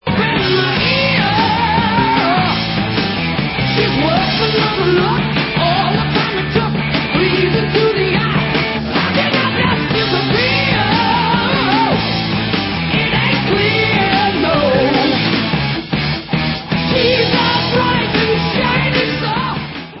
sledovat novinky v oddělení Hard Rock